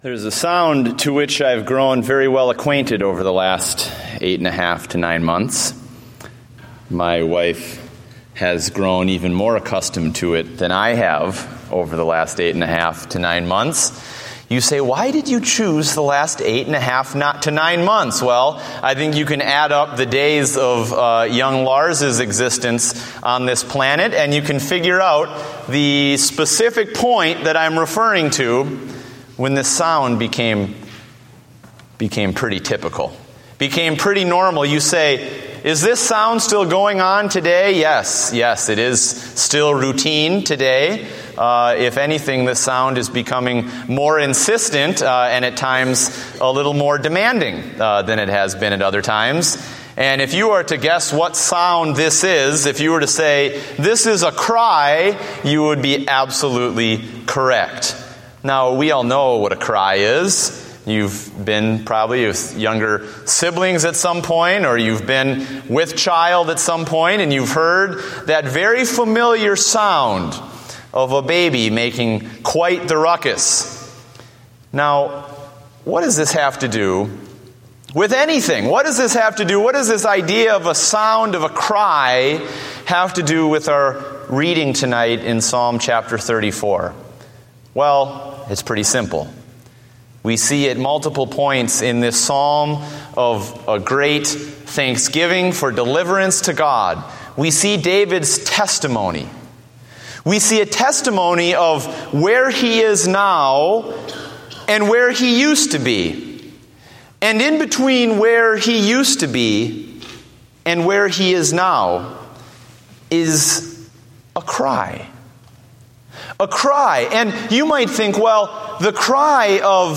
Date: July 27, 2014 (Evening Service)